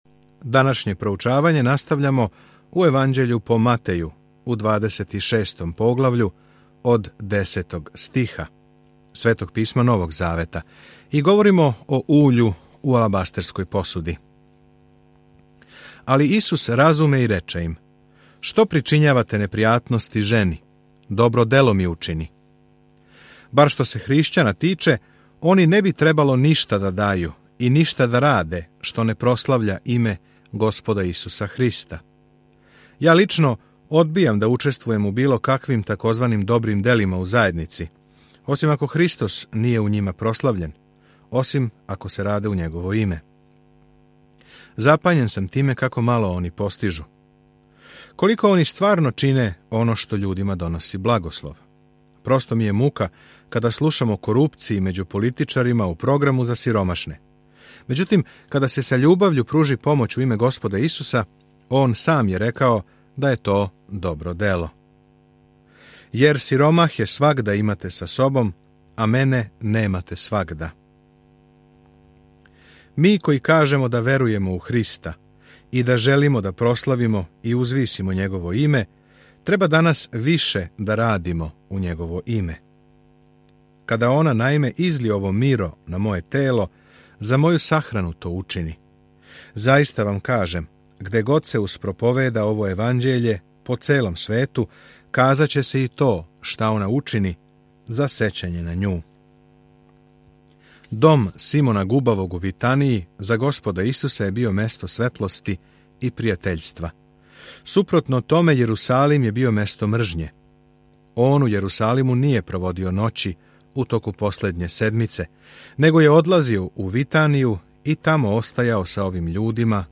Sveto Pismo Matej 26:10-56 Dan 37 Započni ovaj plan Dan 39 O ovom planu Матеј доказује јеврејским читаоцима добру вест да је Исус њихов Месија показујући како су Његов живот и служба испунили старозаветно пророчанство. Свакодневно путујте кроз Матеја док слушате аудио студију и читате одабране стихове из Божје речи.